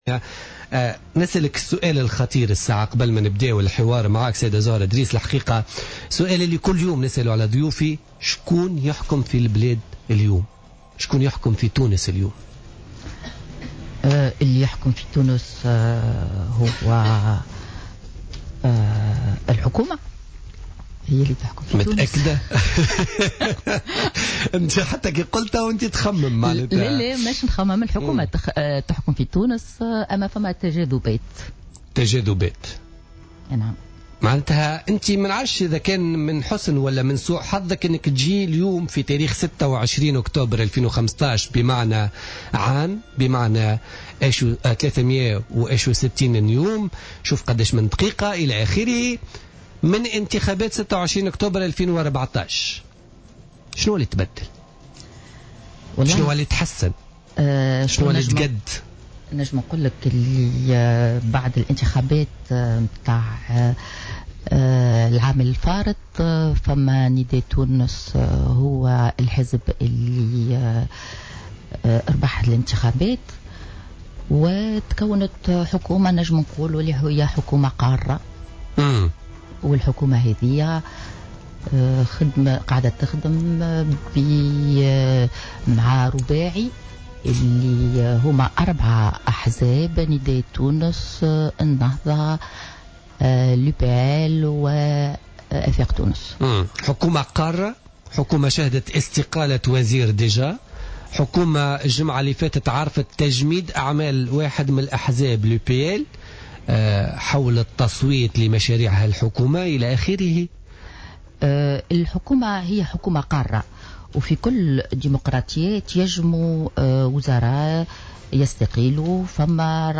أكدت النائبة عن نداء تونس وسيدة الأعمال زهرة ادريس ضيفة بوليتيكا اليوم الإثنين 26 أكتوبر 2015 أن لاستقالات والإقالات من حكومة الحبيب الصيد أمر طبيعي في كل حكومة قارة وديمقراطية موضحة أن استقالة او اقالة وزير لا ينقص من شأن الحكومة في شىء ويحدث في كل الديمقراطيات.